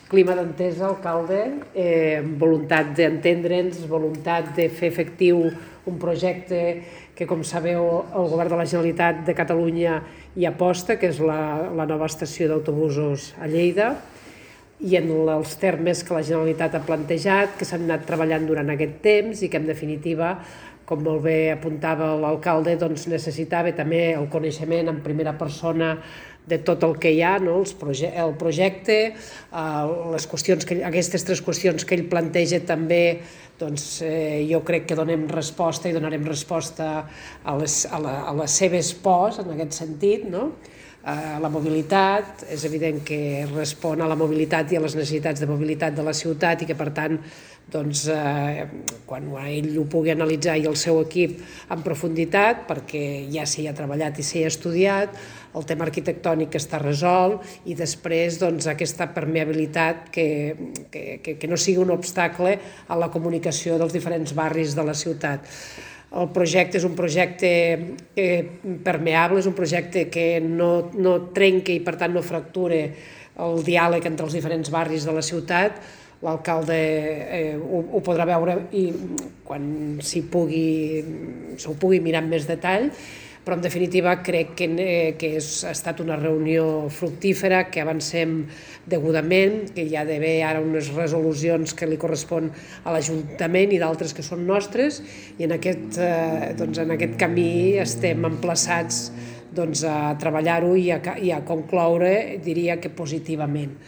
Tall de veu del paer en cap, Fèlix Larrosa, sobre la reunió amb la consellera de Territori, Ester Capella (2.0 MB) Tall de veu de la consellera de Territori, Ester Capella, sobre la reunió amb el paer en cap, Fèlix Larrosa (1.7 MB)